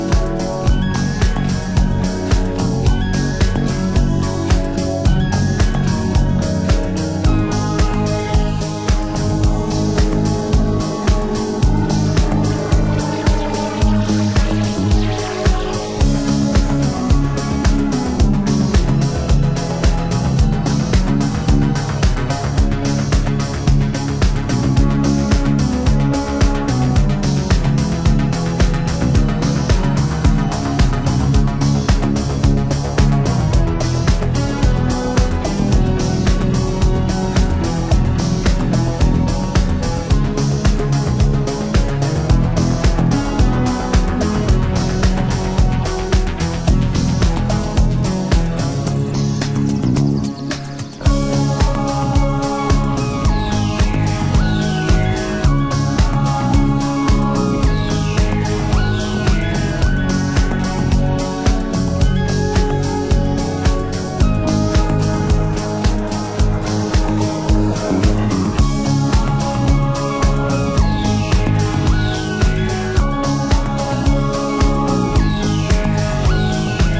★DISOCDUB NUHOUSE